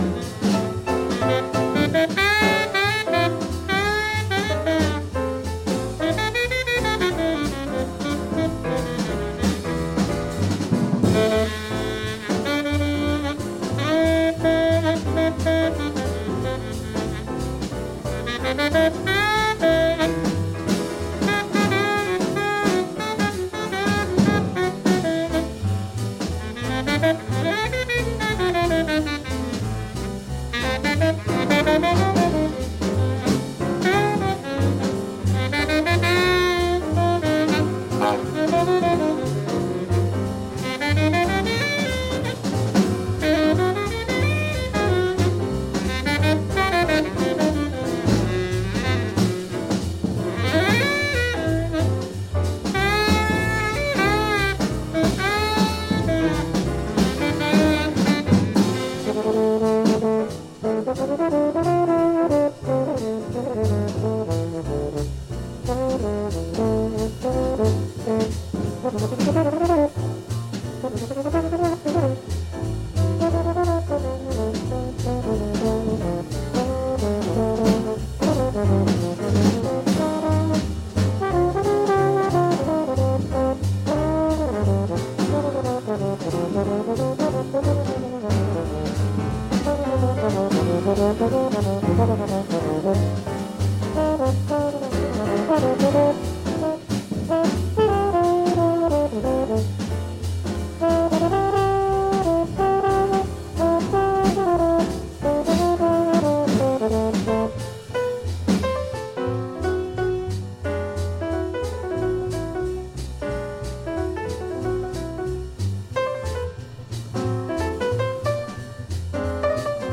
on tenor sax
trombone
piano
bass
drums